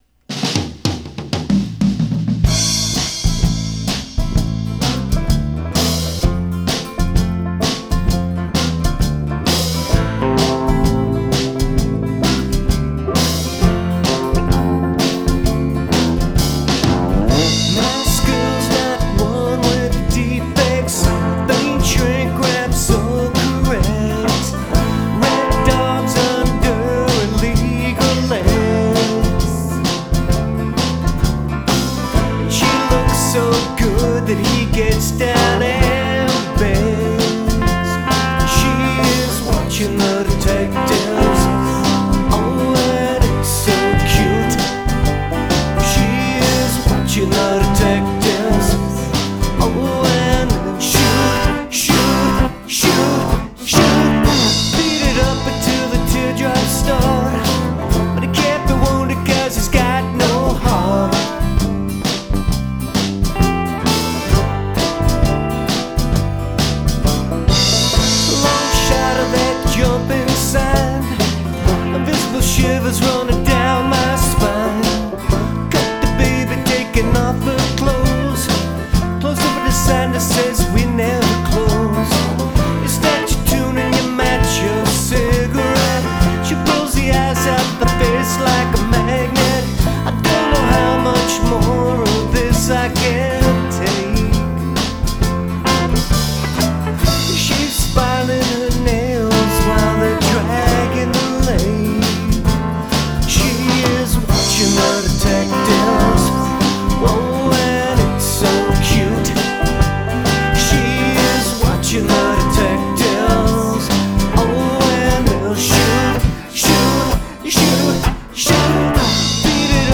Smooth Rock & Natural Roll
electric guitar and vocals
accoustic guitar and vocals
bass and vocals
banjo
percussion
guitar and vocals